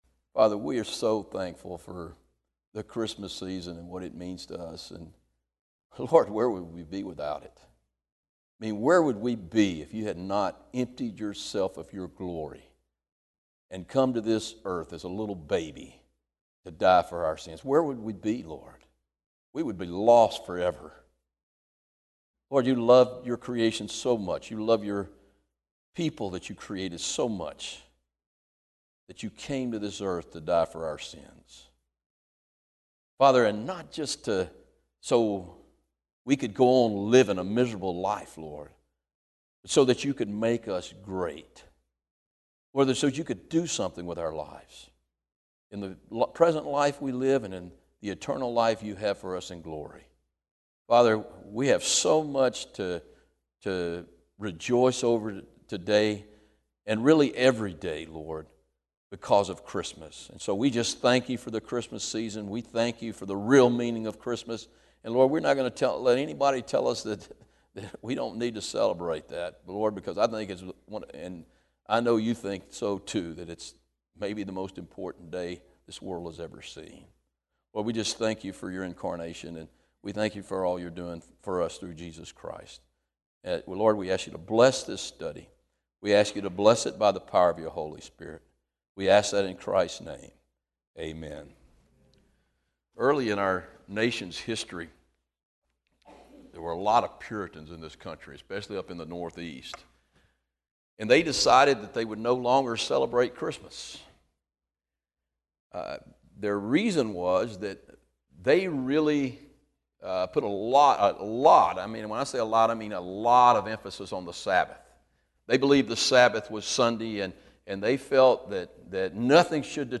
Christmas message from December 21, 2014.